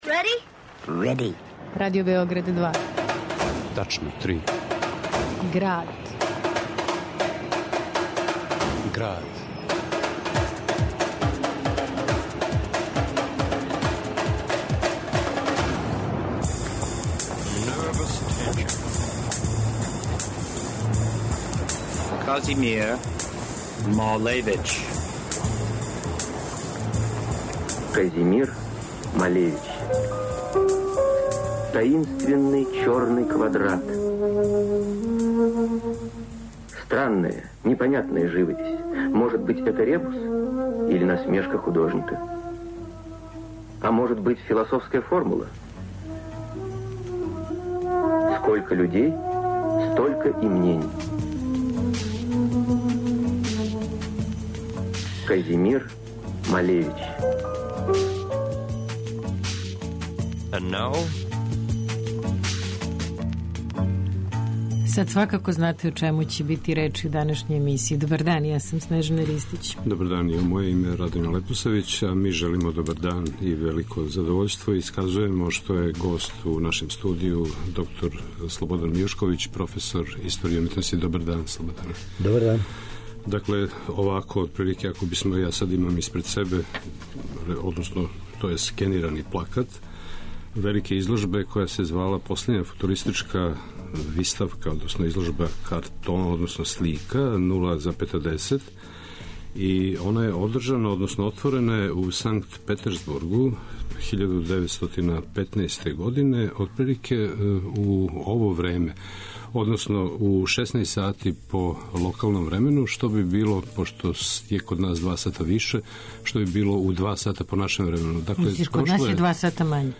уз обиље архивских снимака